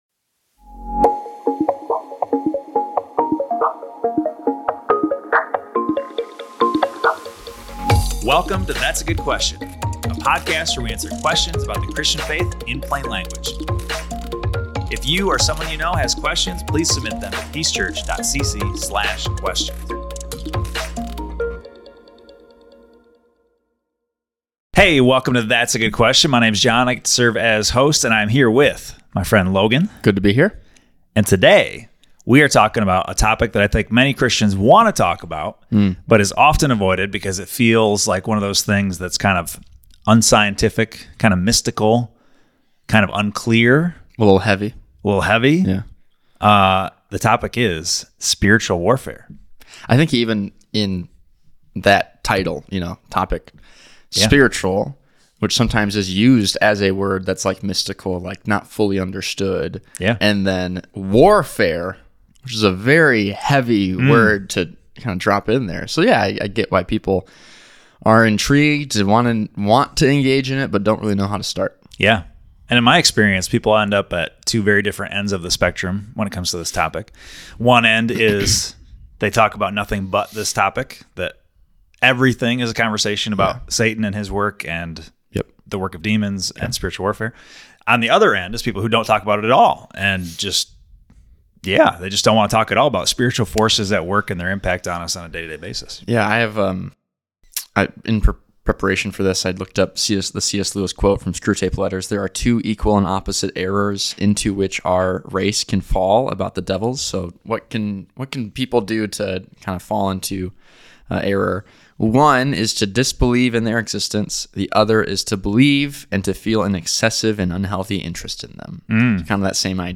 Join the conversation as they tackle listener questions about God’s sovereignty, the authority of Christ, and the ongoing reality of spiritual battles today.